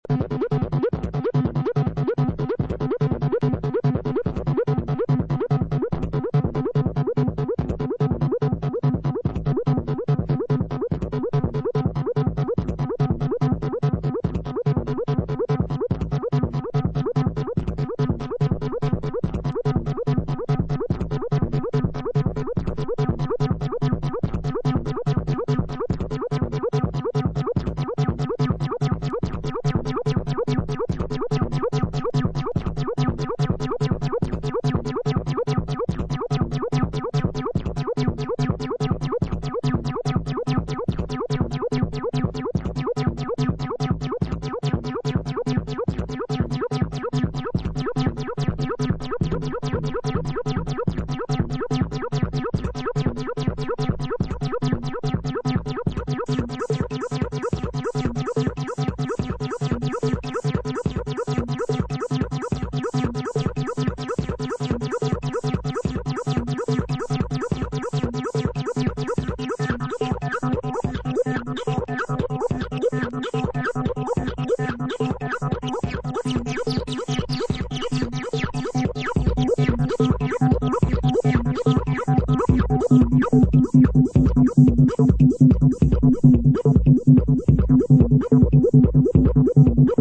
This EP features five bangin' acid tracks!
House Acid Chicago